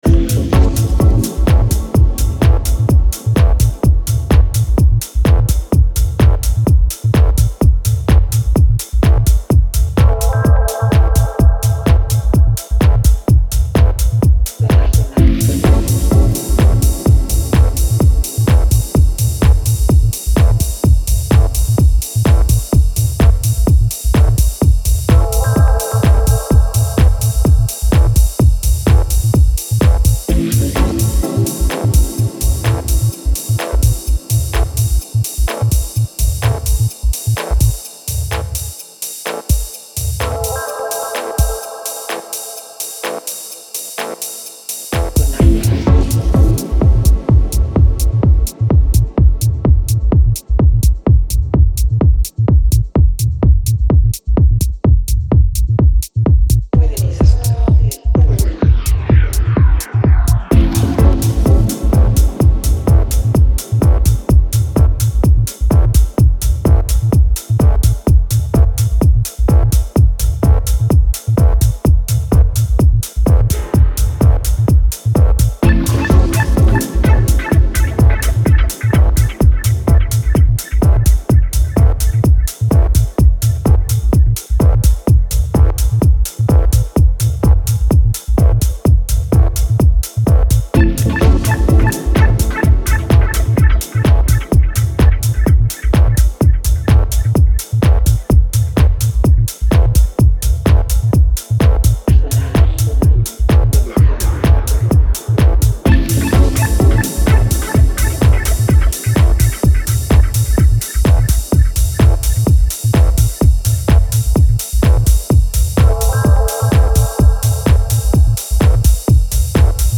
Deep and Dub Techno